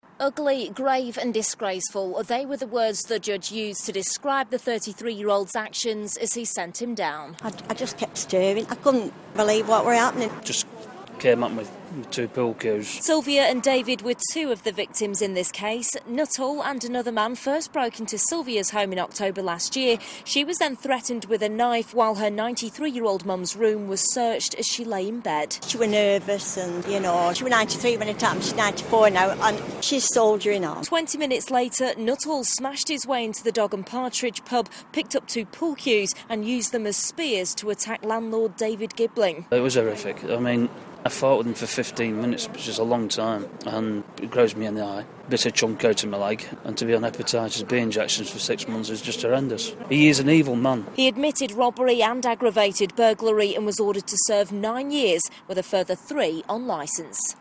Court report